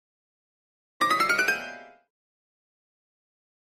Comedy Piano Chromatic Run Up 1 - Climbing